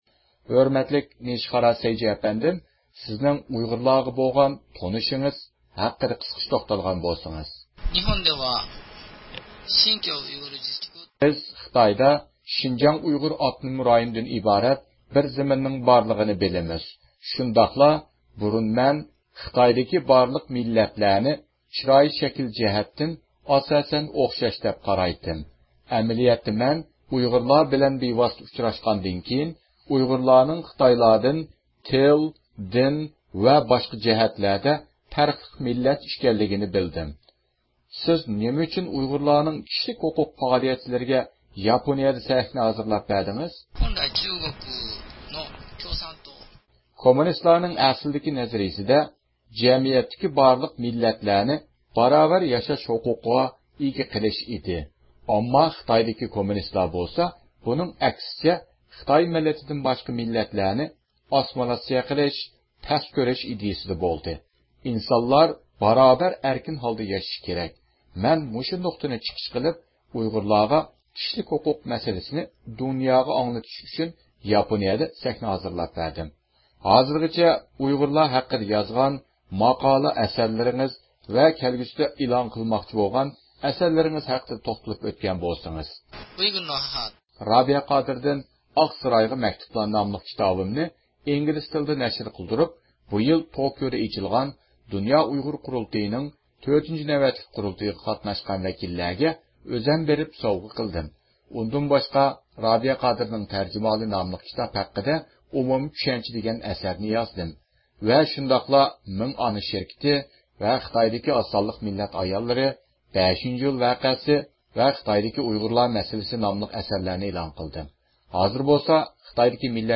ئۇيغۇرلار ھەققىدە سۆھبەت – ئۇيغۇر مىللى ھەركىتى